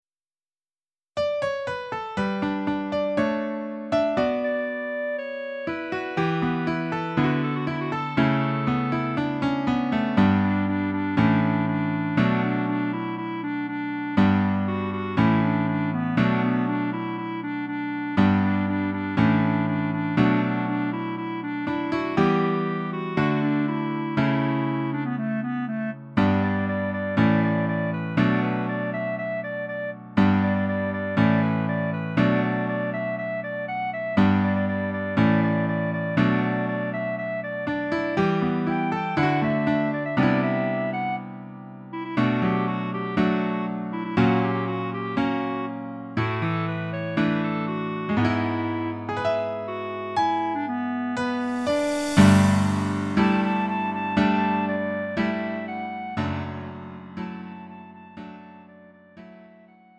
음정 원키 4:00
장르 구분 Lite MR